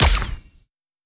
Hitech Shot A.wav